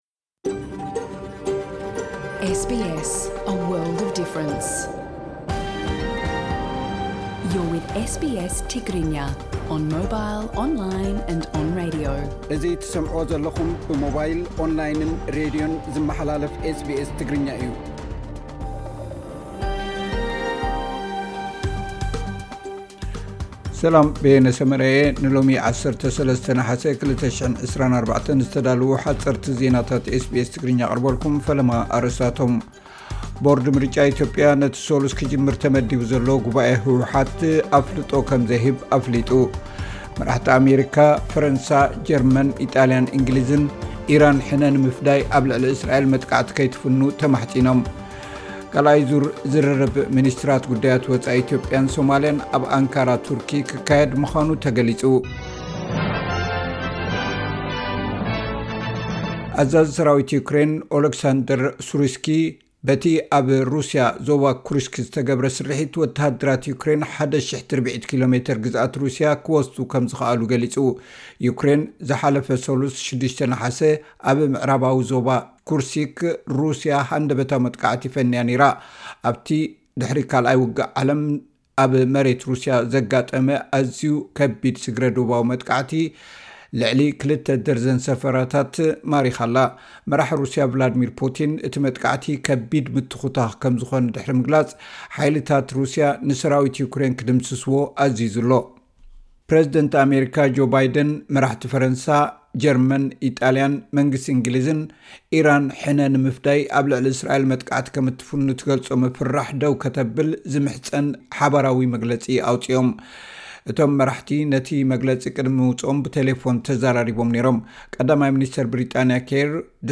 ሓጸርቲ ዜናታት ኤስ ቢ ኤስ ትግርኛ (13 ነሓሰ 2024)